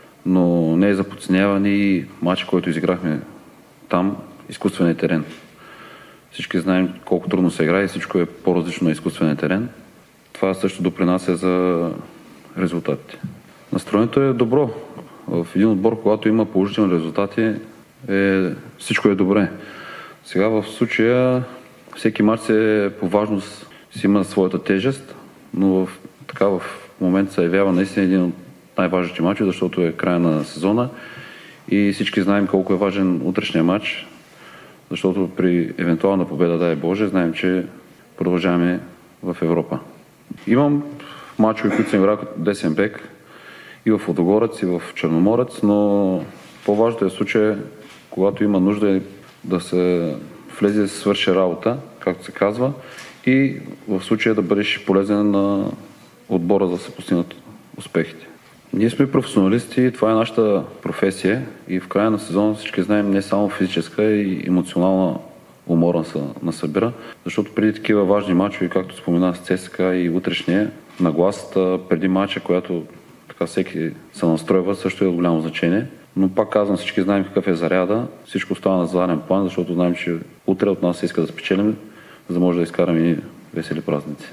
Защитникът на Лудогорец Георги Терзиев присъства на официалната пресконференция на Лудогорец преди домакинството на Нордселанд.